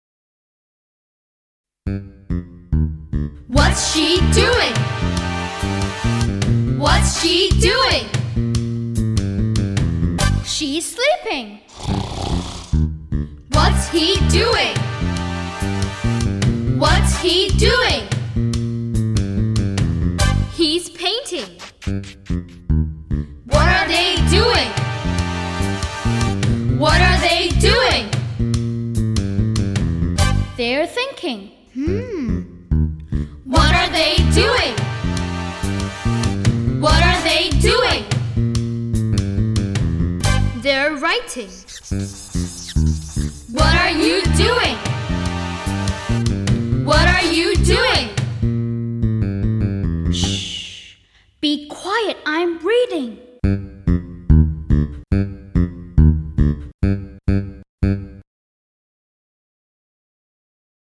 UNIT 5 SONG